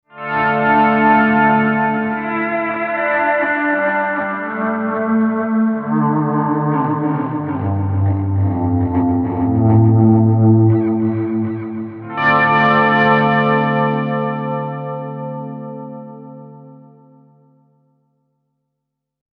Here are a few soundbites using some of the factory patches:
Volume Pad – LP-type guitar
volume-pad-e28093-lp-type.mp3